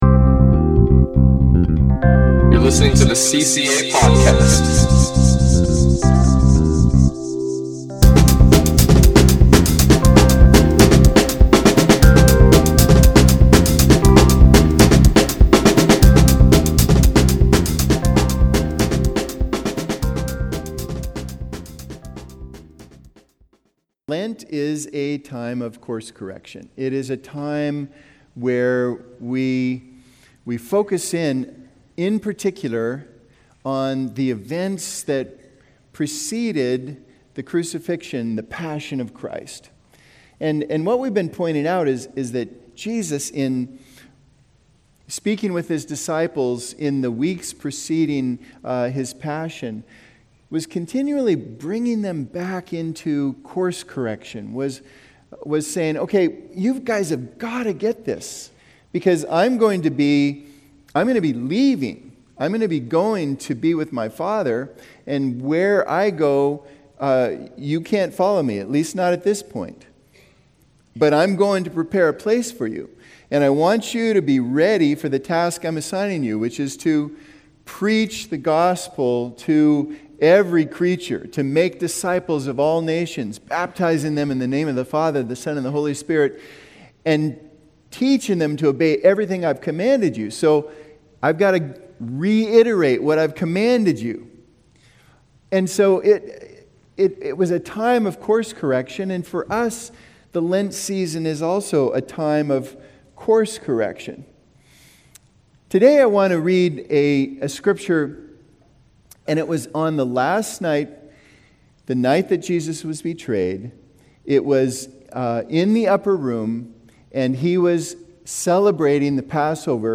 In this week’s sermon